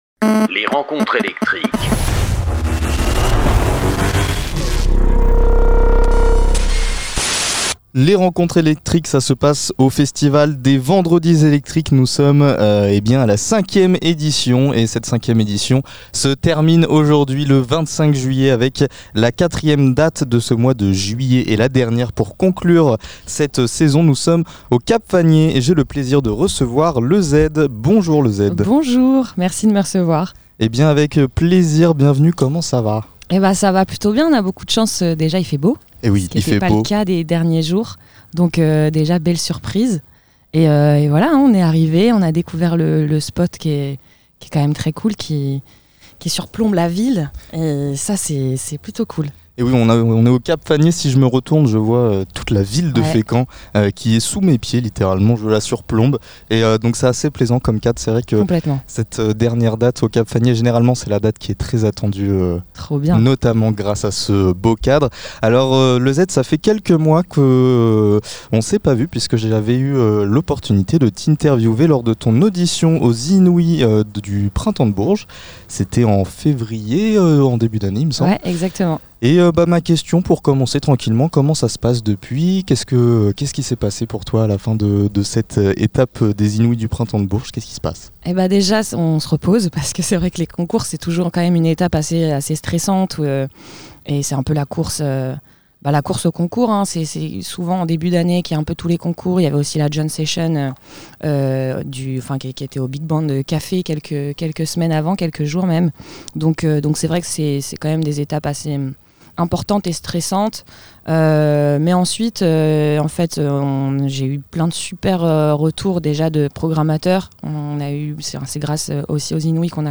Les rencontres électriques sont les interviews des artistes régionaux qui se produisent lors du festival "Les vendredi électriques" organisés par l'association Art en Sort.